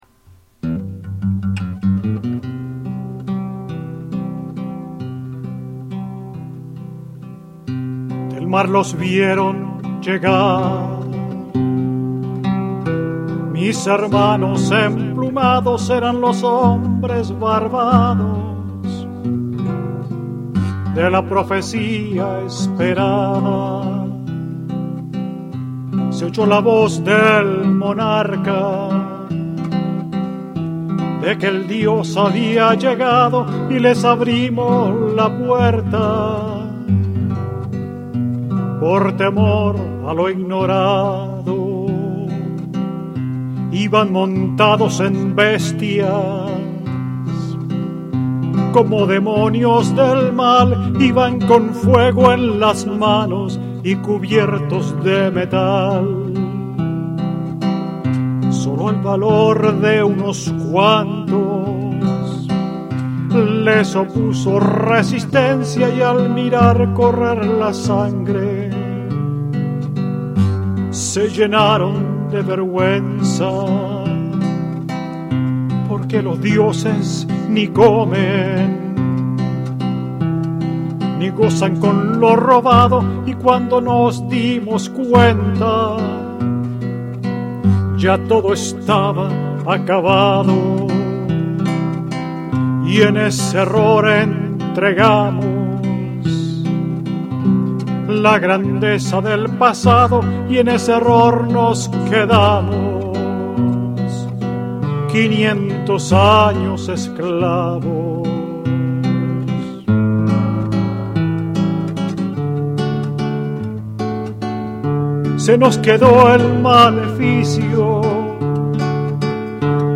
Mexico in the Heart on the Presentation of "No Word for Welcome" in Boston